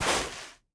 Minecraft Version Minecraft Version 1.21.5 Latest Release | Latest Snapshot 1.21.5 / assets / minecraft / sounds / block / soul_sand / step5.ogg Compare With Compare With Latest Release | Latest Snapshot